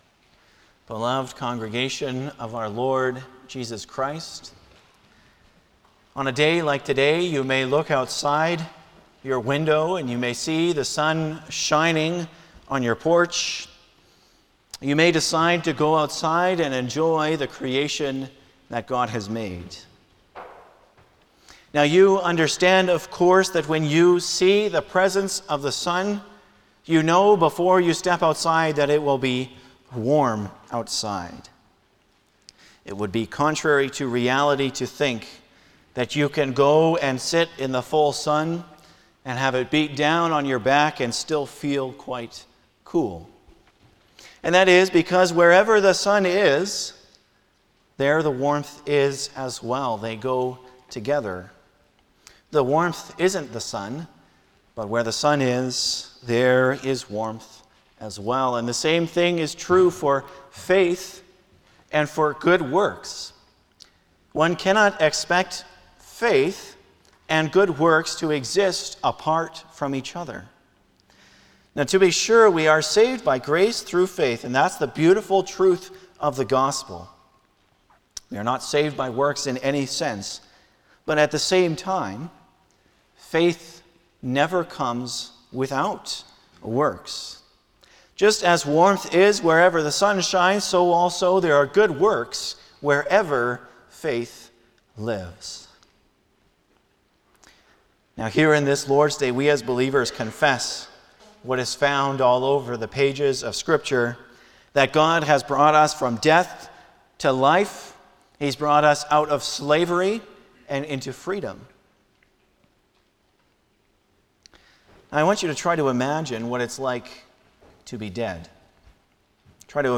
Passage: Lord’s Day 32 Service Type: Sunday afternoon
07-Sermon.mp3